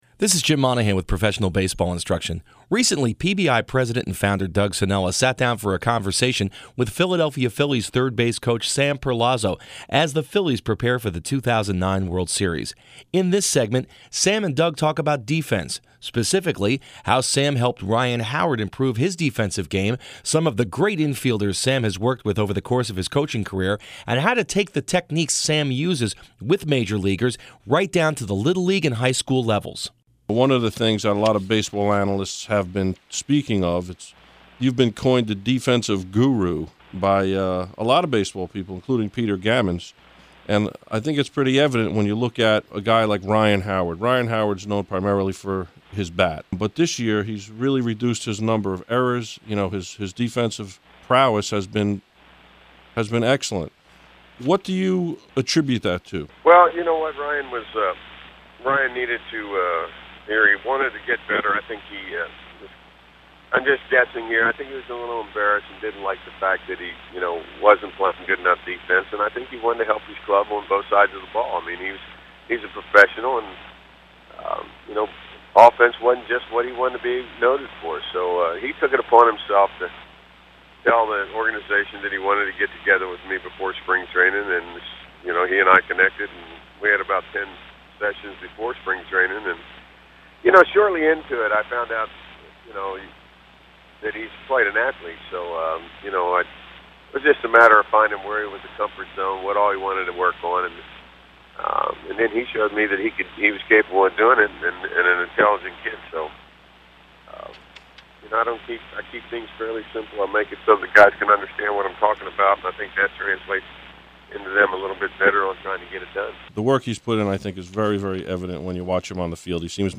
Special thanks to WDHA for their assistance in producing these interview segments.